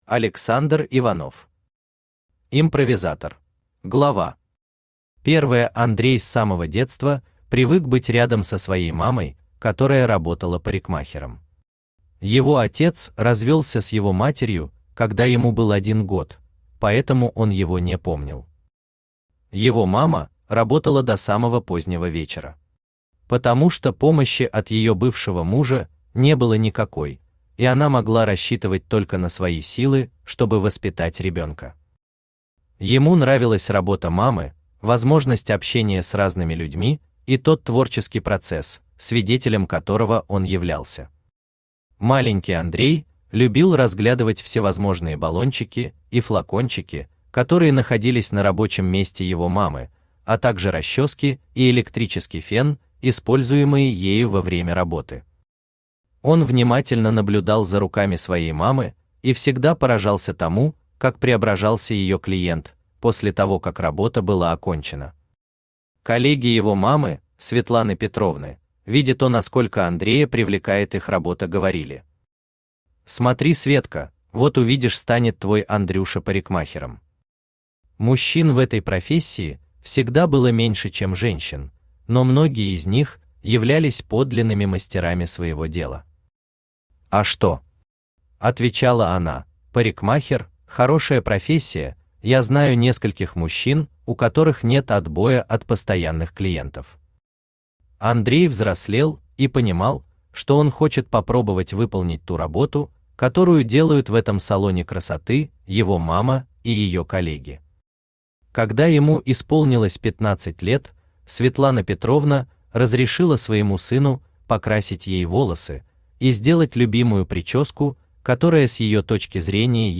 Аудиокнига Импровизатор | Библиотека аудиокниг
Aудиокнига Импровизатор Автор Александр Иванович Иванов Читает аудиокнигу Авточтец ЛитРес.